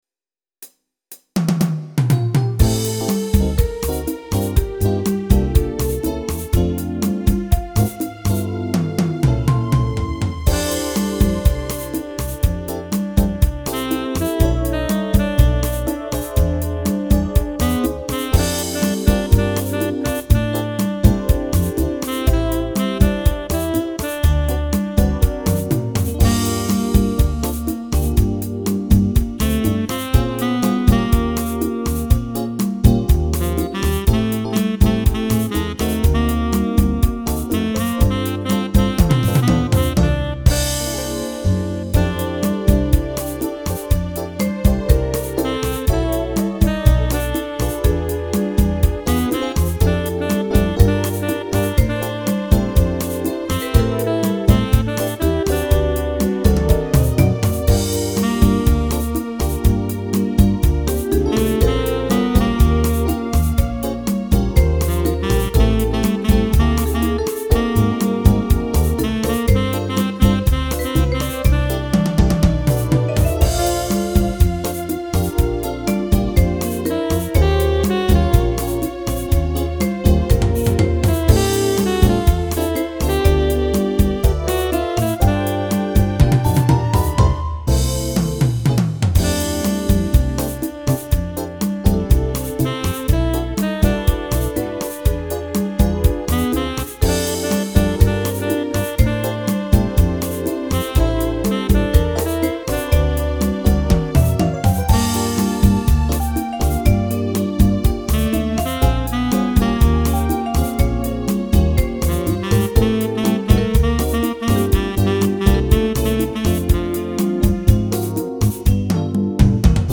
th45U0JU44  Download with Lead